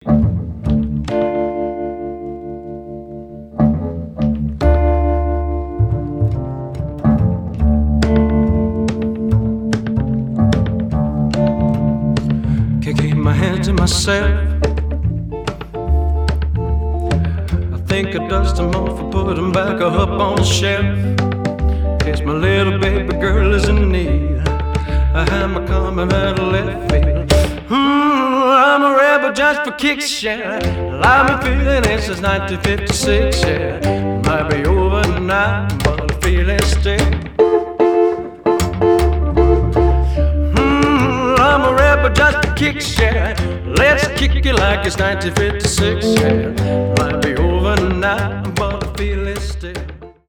in the distinct Memphis sound
Post-Modern Jukebox feel